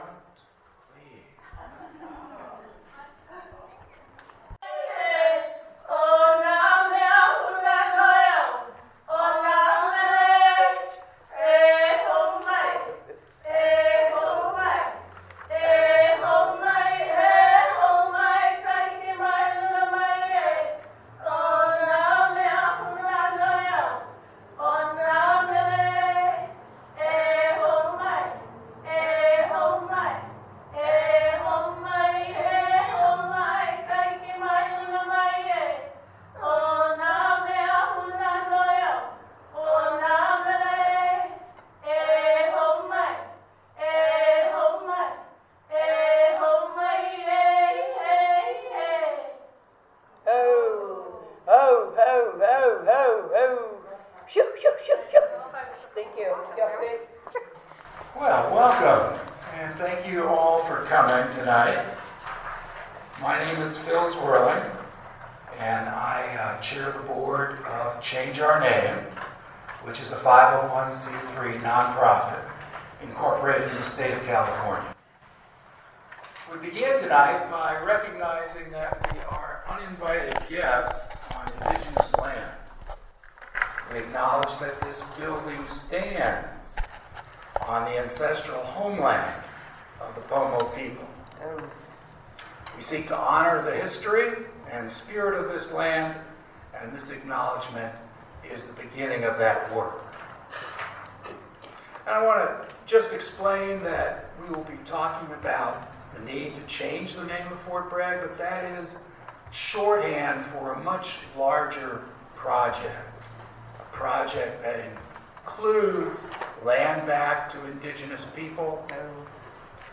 Change Our Name’s Monthly Teach-In Friday May 17